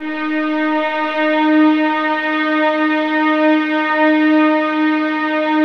VIOLINS FN-L.wav